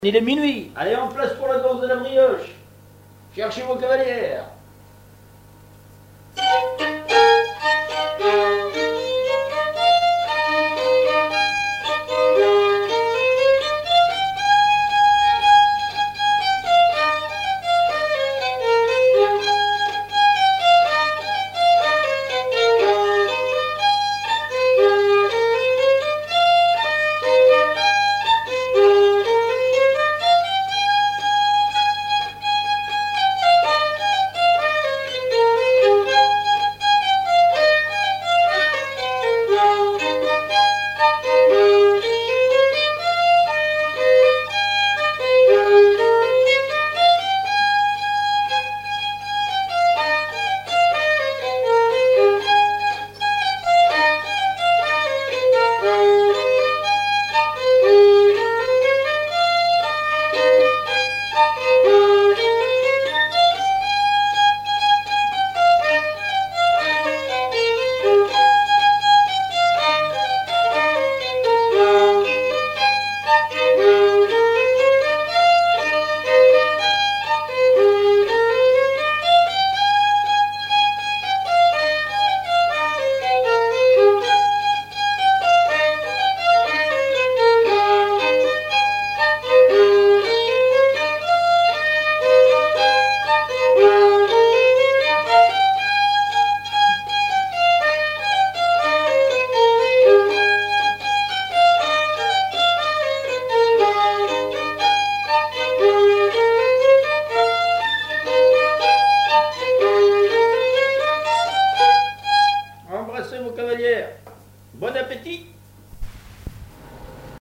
Chants brefs - A danser
danse : branle
Auto-enregistrement
Pièce musicale inédite